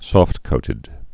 (sôftkōtĭd, sŏft-)